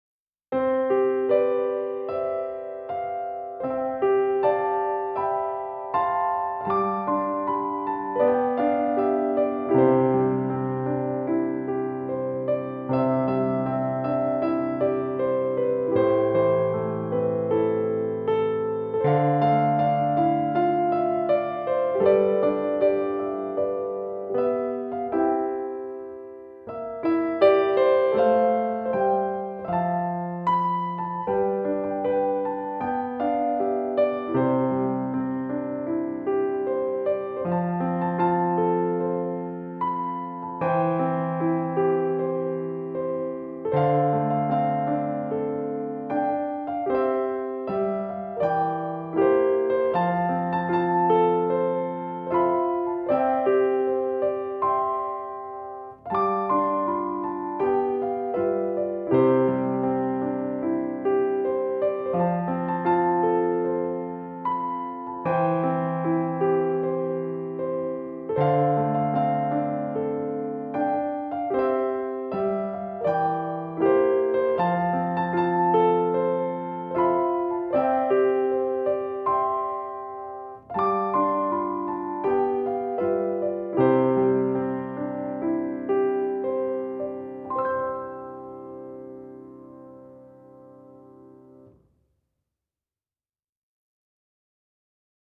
2曲とも、アップテンポで耳馴染みが良く、口ずさみたくなる楽しい曲となっています。
BGMアレンジ2